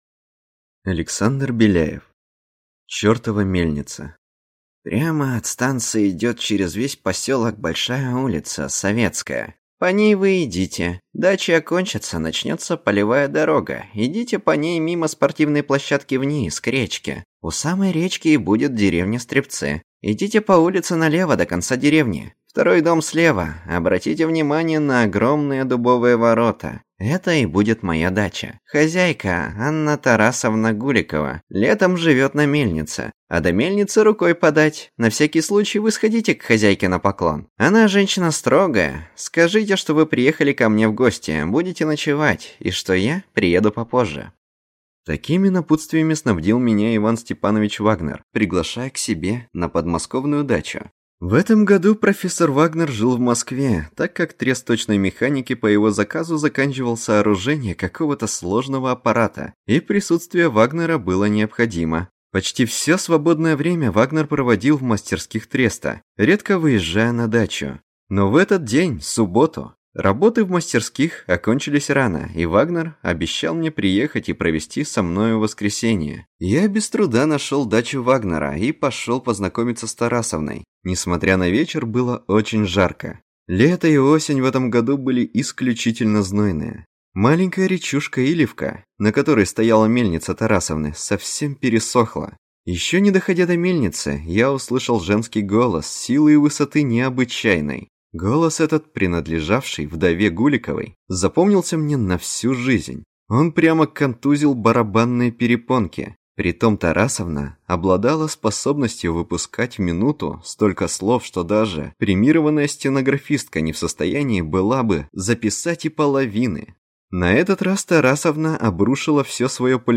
Аудиокнига Чёртова мельница | Библиотека аудиокниг
Aудиокнига Чёртова мельница Автор Александр Беляев Читает аудиокнигу Междустрочие.